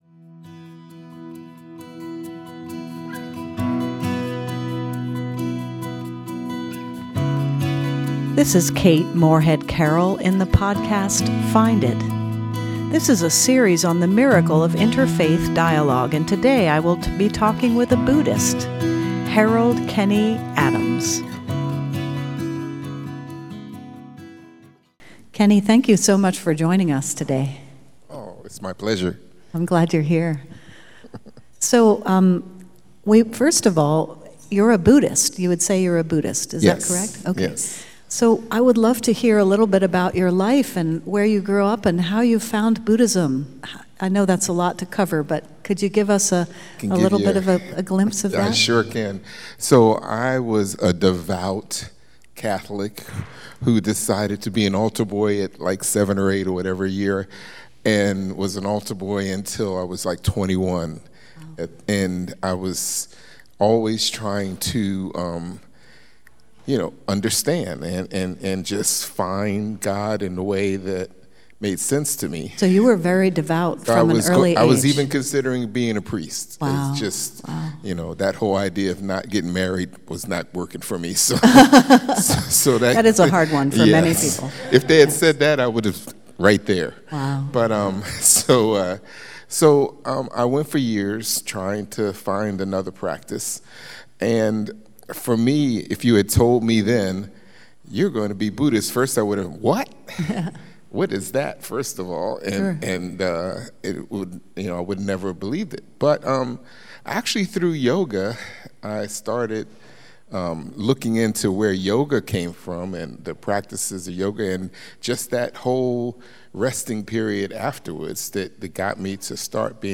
This episode includes a guided meditation.